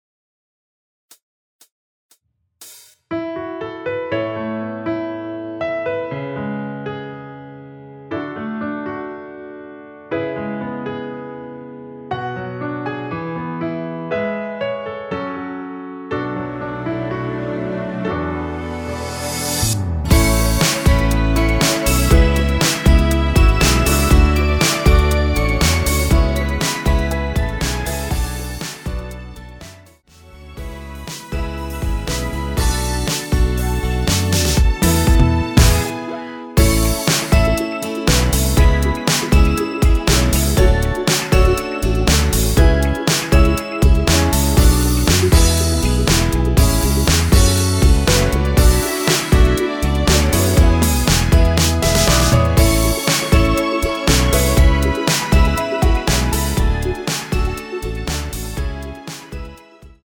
전주 없이 시작 하는곡이라 카운트 4박 넣어 놓았습니다.(미리듣기 참조)
◈ 곡명 옆 (-1)은 반음 내림, (+1)은 반음 올림 입니다.
앞부분30초, 뒷부분30초씩 편집해서 올려 드리고 있습니다.
중간에 음이 끈어지고 다시 나오는 이유는